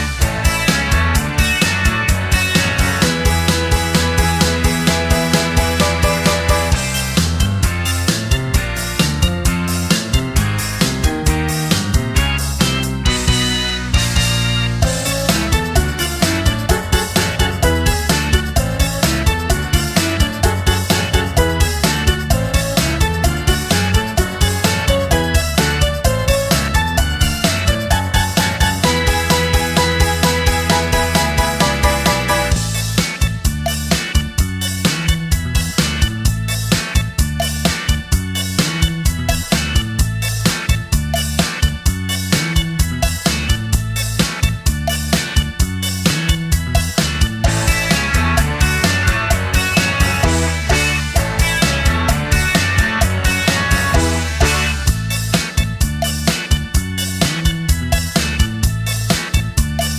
no saxes Ska 2:33 Buy £1.50